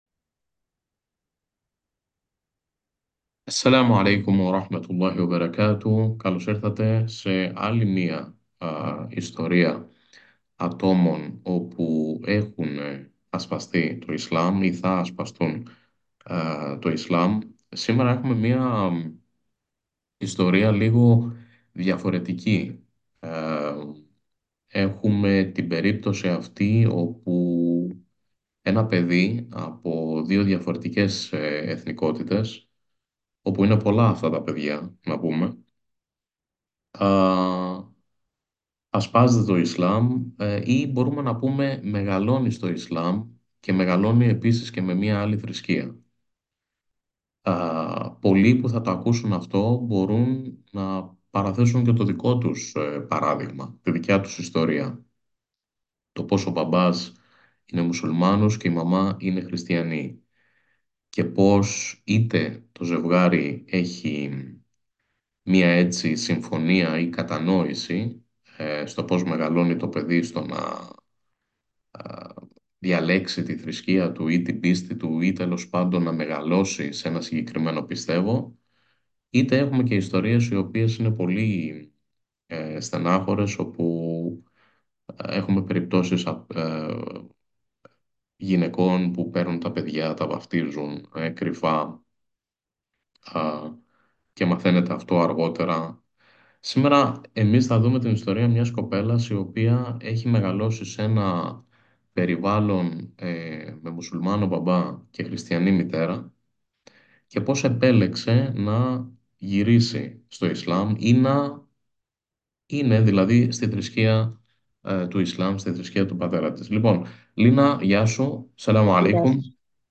Ακολουθεί το ηχητικό της συζήτησης.